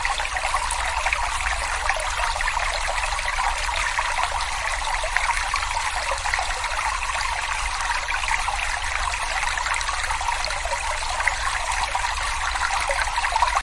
nature » Brook in cave
标签： flowing water flow cave brook stream
声道立体声